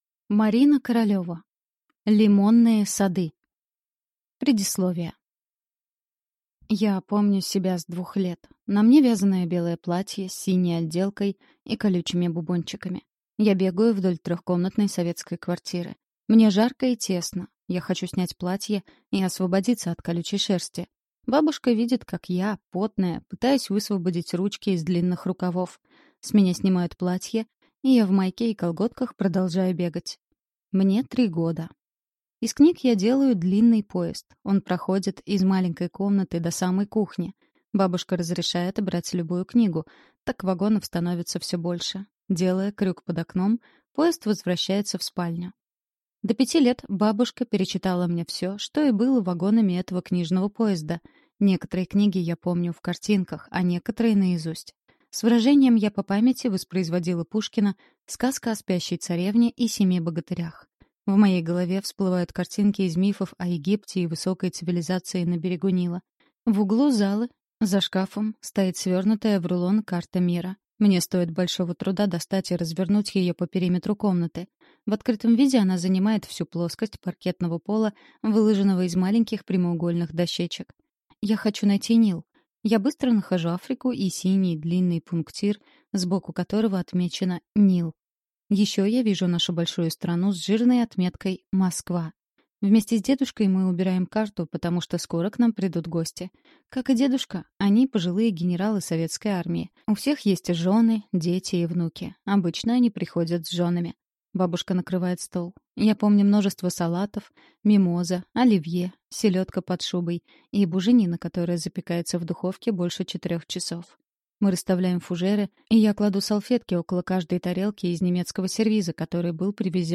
Аудиокнига Лимонные Сады | Библиотека аудиокниг